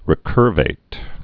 (rĭ-kûrvāt, -vĭt)